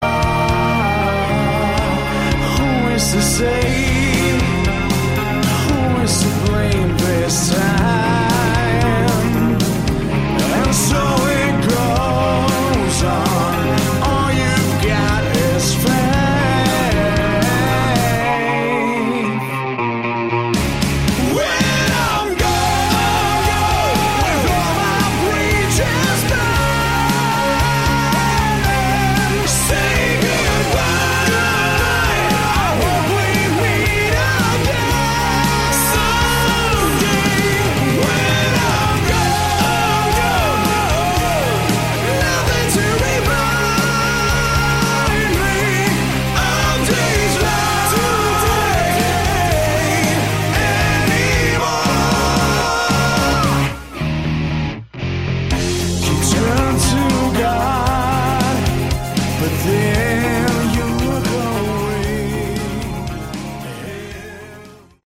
Category: Hard Rock
vocals
guitars
keyboards
bass
drums
Never heard such bad bad voice!!!derrible!!!!